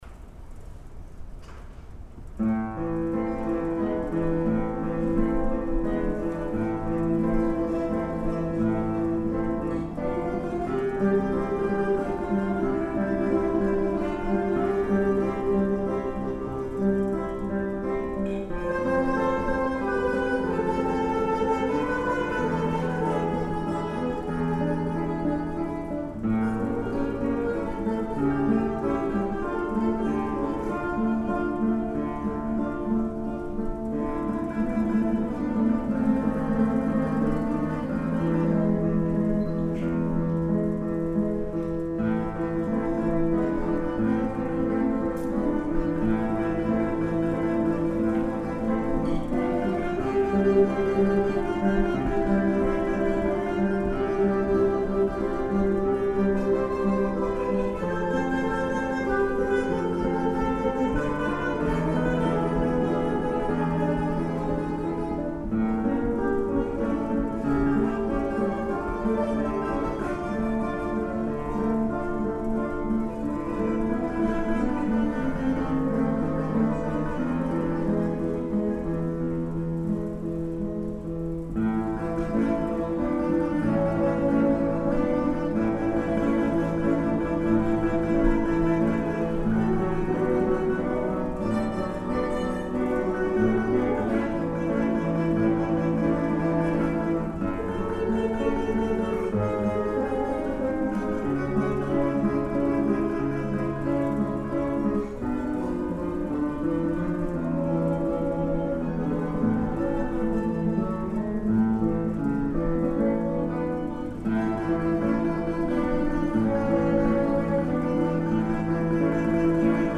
ギターコンサート
ensemble